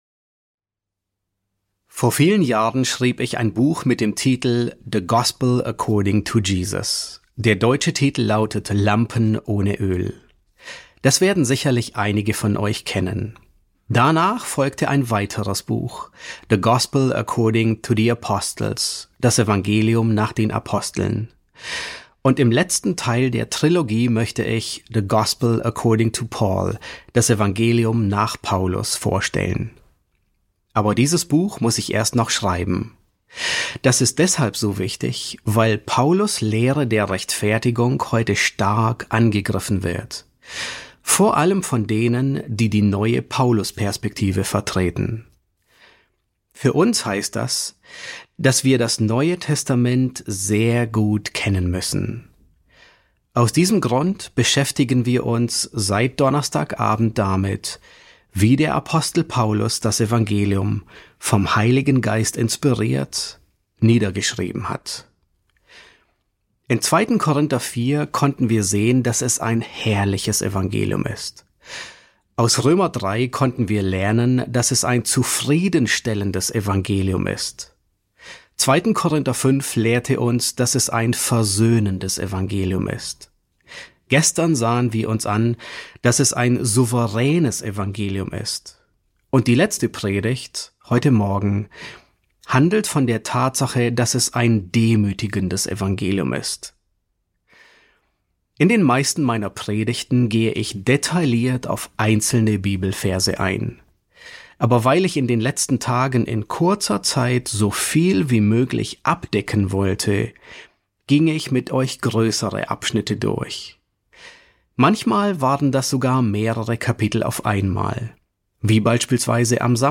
S8 F7 | Das demütigende Evangelium ~ John MacArthur Predigten auf Deutsch Podcast